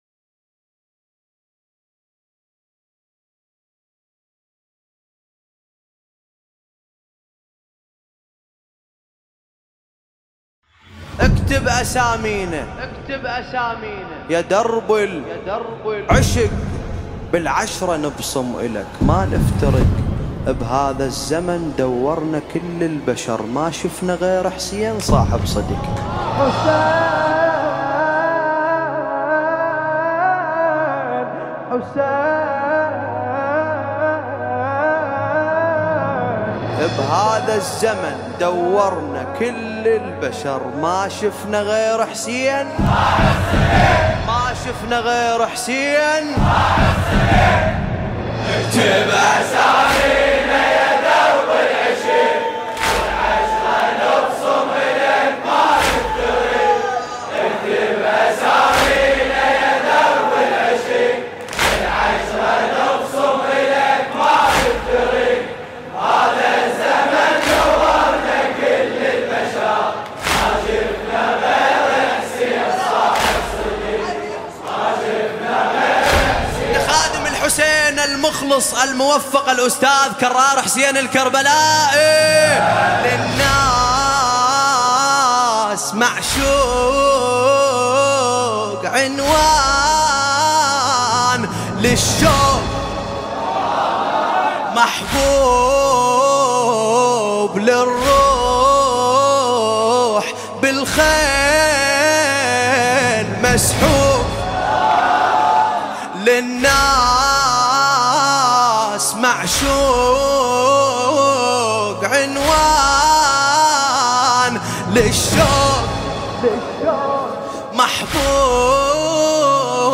مداحی عربی
نوای دلنشین